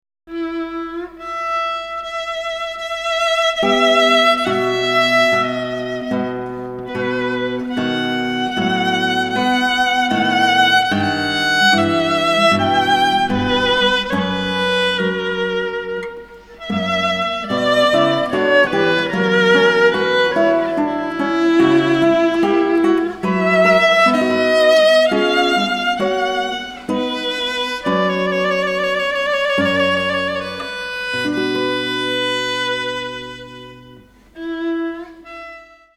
LISTEN 1 Largo